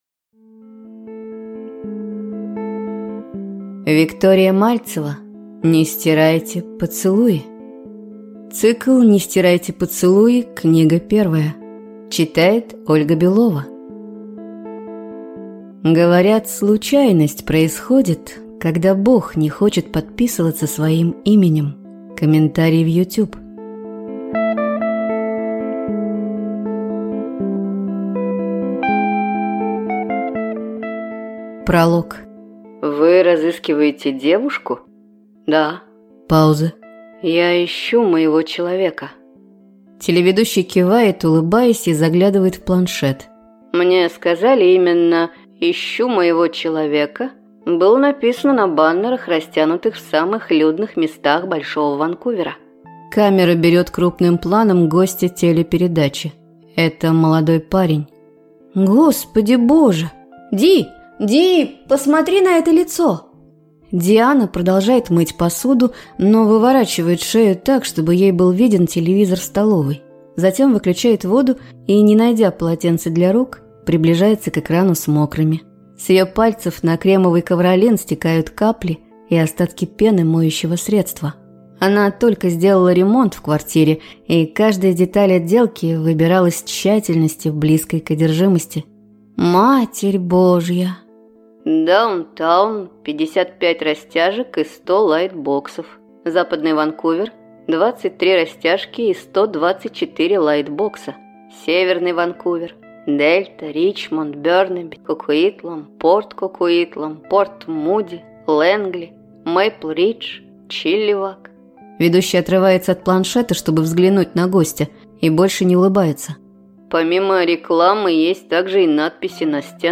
Аудиокнига Не стирайте поцелуи. Книга 1 | Библиотека аудиокниг